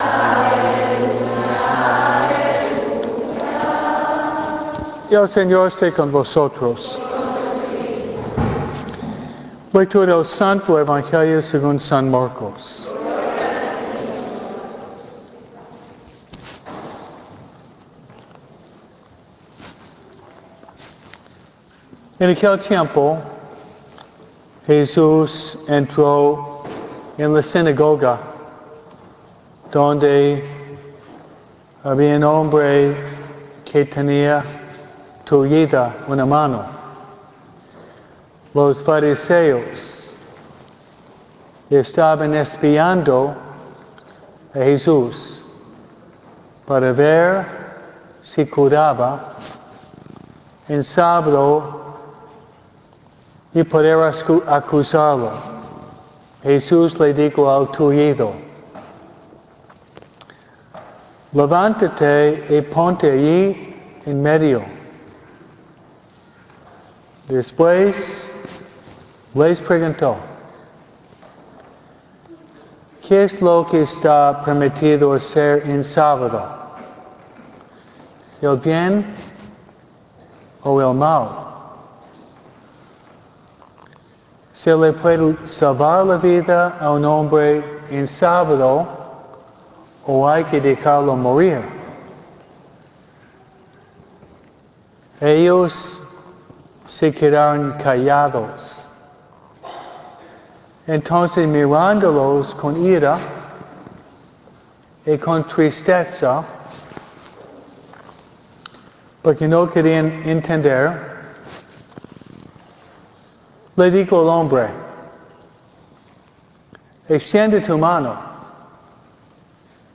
2063S-MISA-DE-NINOS.mp3